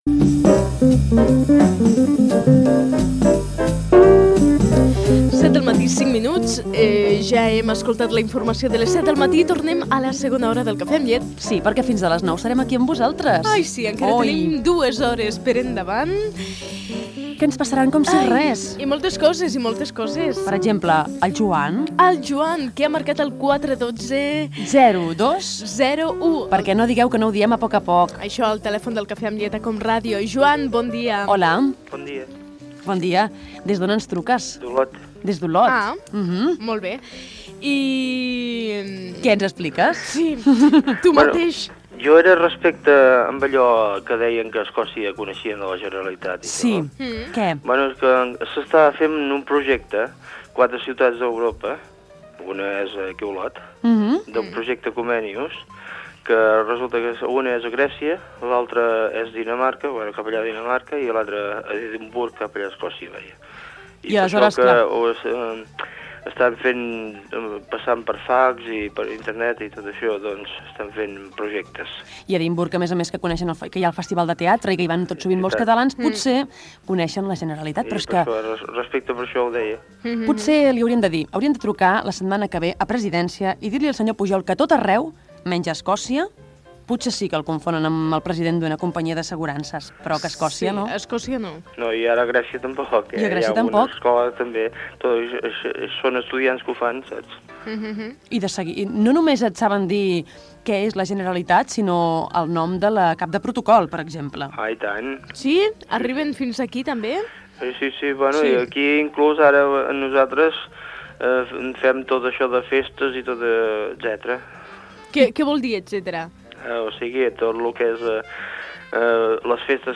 Entreteniment
Programa despertador dels diumenges.